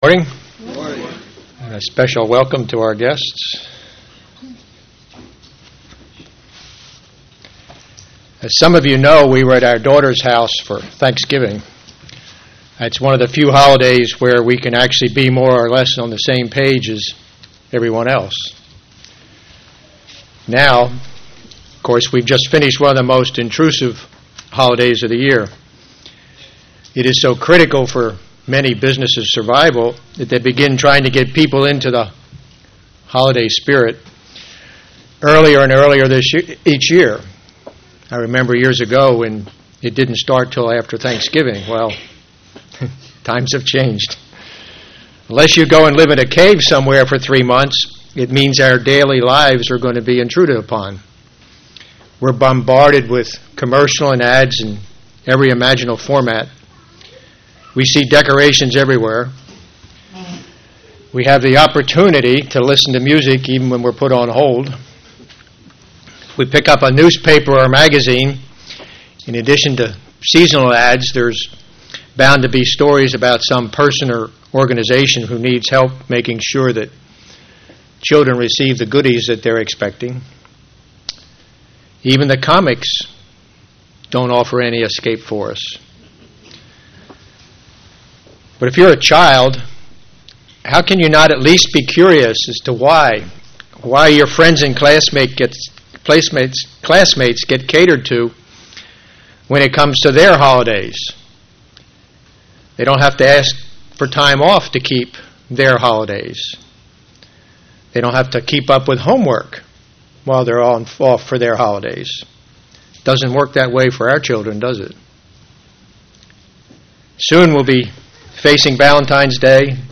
Given in St. Petersburg, FL
Instead, we are to worship God in the same manner that Christ and the Apostles did by keeping the Holy Days He commanded UCG Sermon Studying the bible?